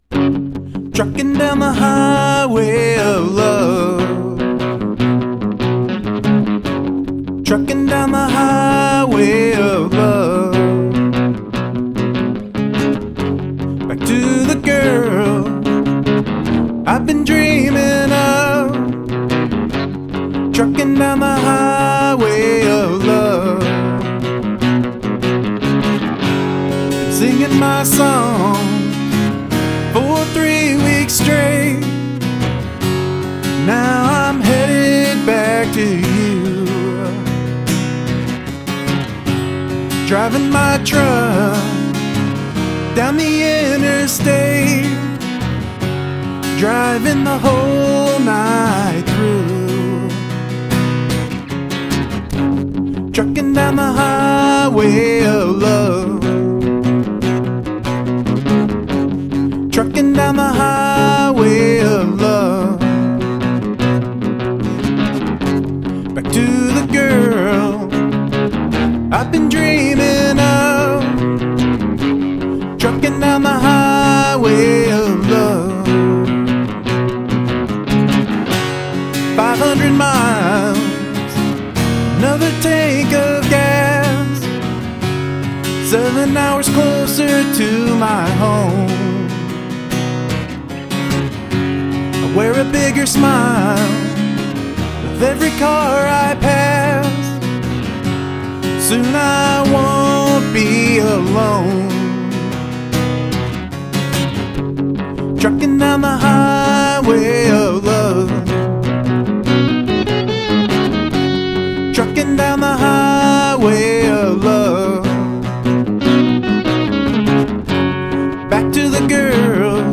It's so hard to do easy sounding pieces of music...
I like how you switch between the chugging guitar and acoustic, nice touch!